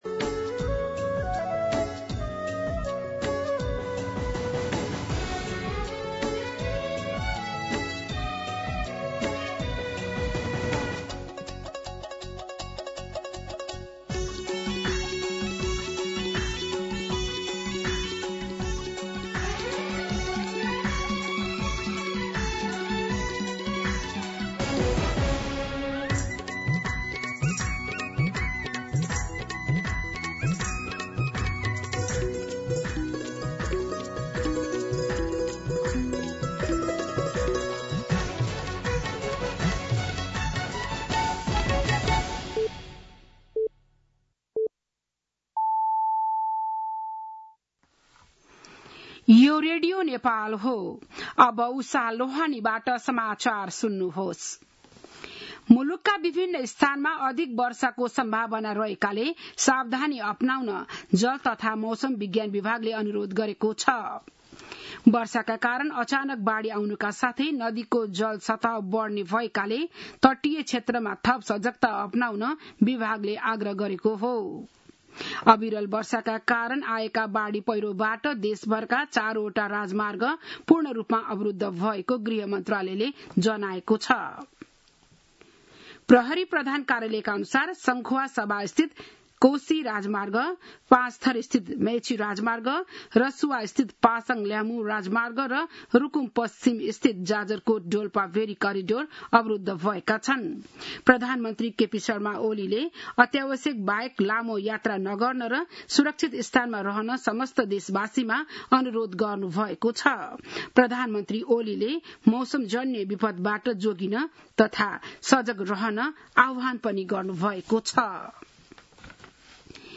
बिहान ११ बजेको नेपाली समाचार : ५ साउन , २०८२